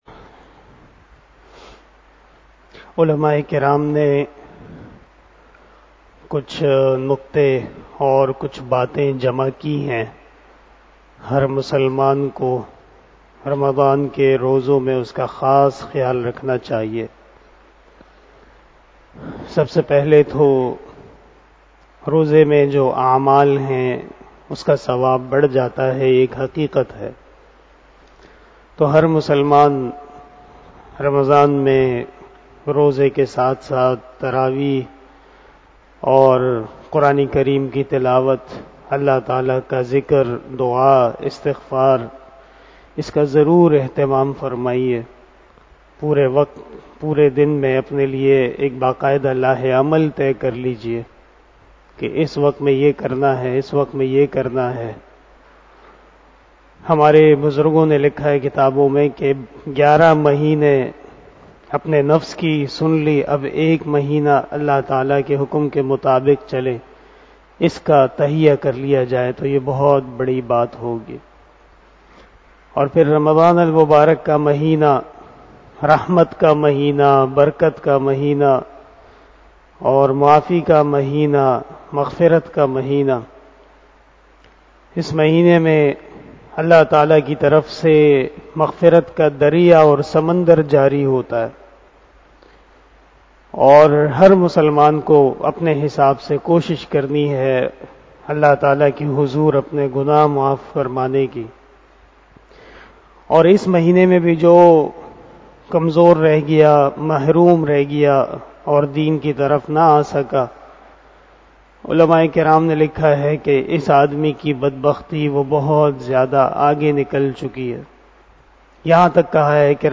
023 After Fajar Namaz Bayan 03 April 2022 ( 01 Ramadan 1443HJ) Sunday